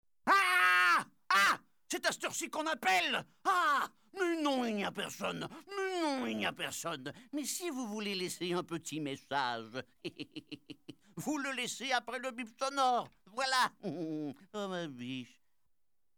Messages d'imitations 1: